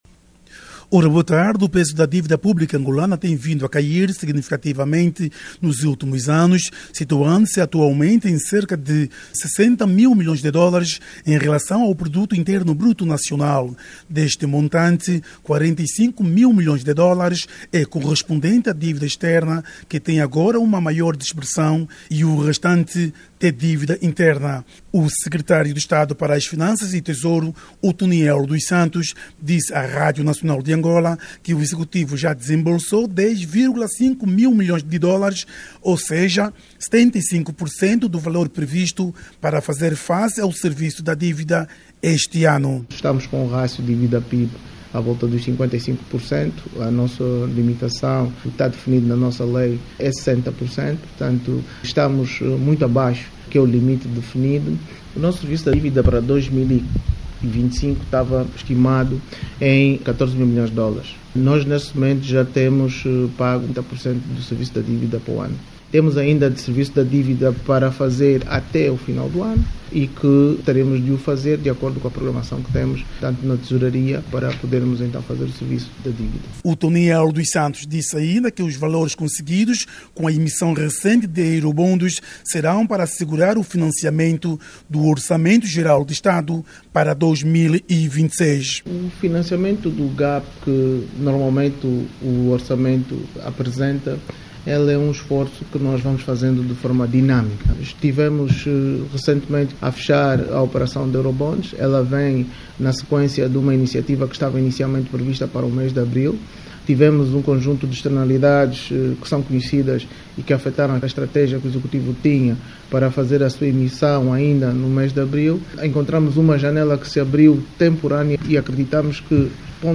a partir da capital norte-americana.